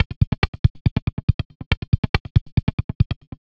tx_perc_140_envclicks.wav